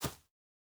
Shoe Step Grass Hard A.wav